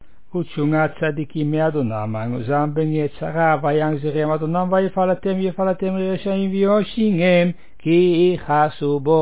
The service at the unveiling of a Matseva (tombstone) at Beth Haim in Ouderkerk aan de Amstel.
In contrast to the mitsva (levaya), there is no singing: all texts are recited.
Recited